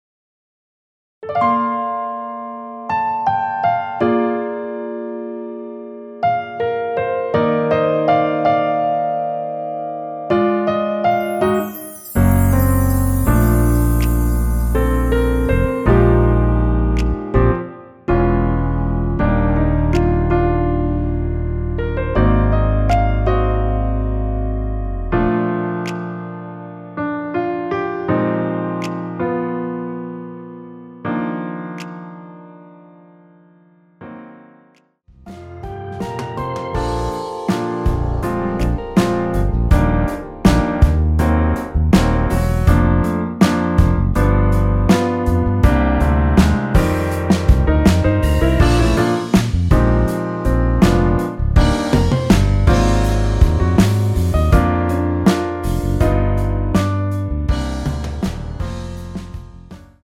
라이브 하시기 좋게 노래 끝나고 바로 끝나게 4분 36초로 편곡 하였습니다.(코러스 MR 미리듣기 참조)
원키에서(-2)내린 MR입니다.
Bb
앞부분30초, 뒷부분30초씩 편집해서 올려 드리고 있습니다.
중간에 음이 끈어지고 다시 나오는 이유는